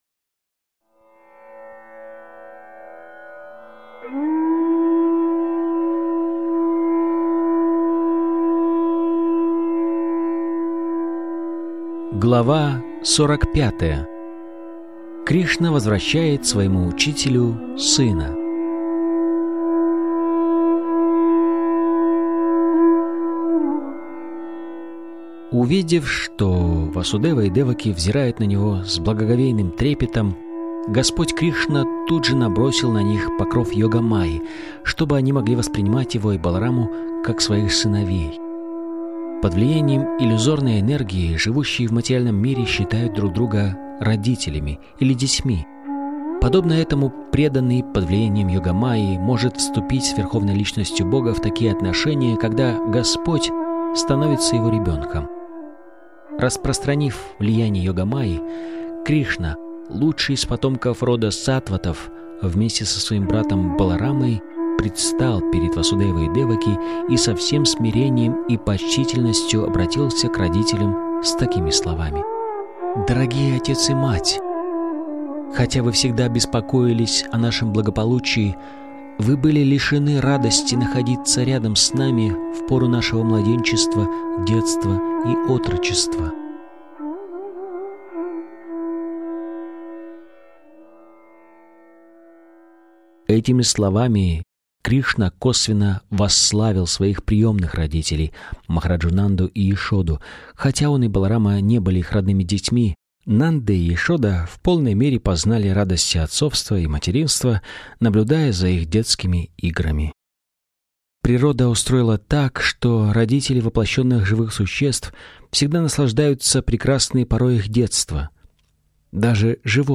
Источник вечного наслаждения автор Абхай Чаран Де, Бхактиведанта Свами Прабхупада Информация о треке Автор аудиокниги : Абхай Чаран Де Бхактиведанта Свами Прабхупада Аудиокнига : Кришна.